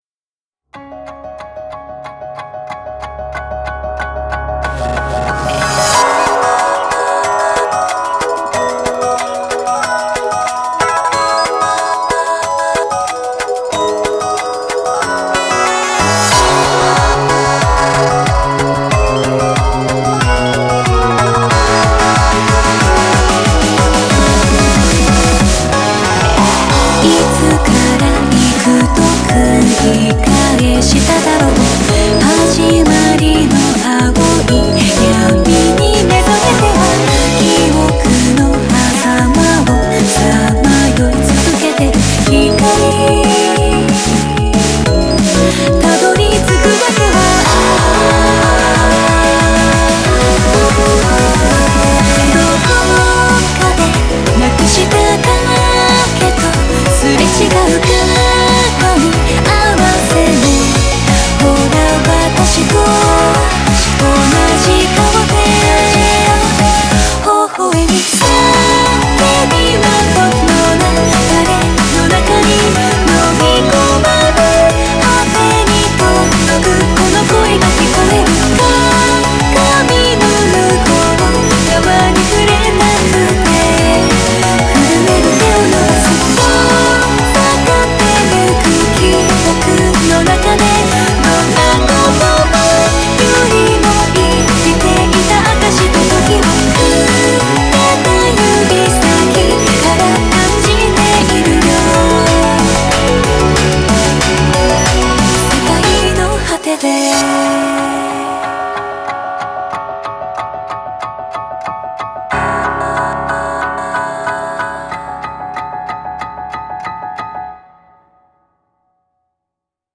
BPM185
Audio QualityPerfect (High Quality)
Genre: HEALING DRUM'N'BASS.